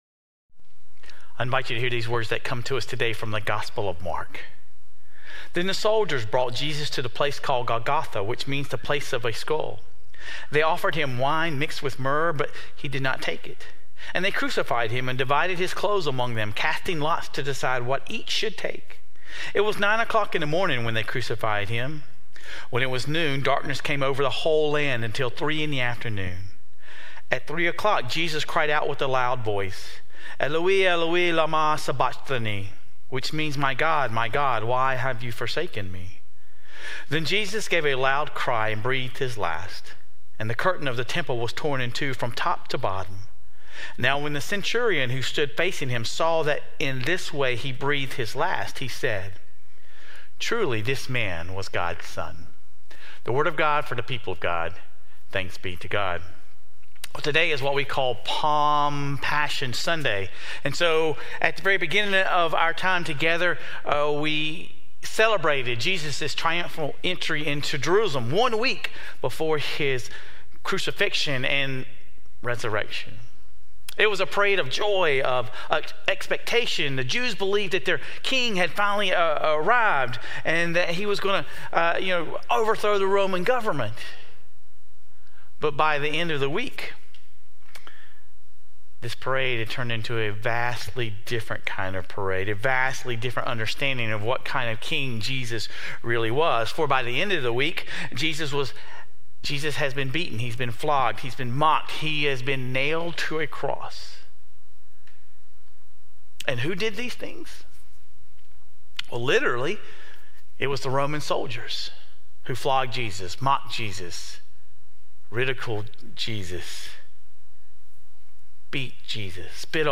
In this final installment of our Lenten series, we turn toward Jesus' final moments and examine the stories of the soldiers at the foot of the cross. Sermon Reflections: The Roman soldiers gambled over Jesus' clothes, something that seems so trivial and unimportant.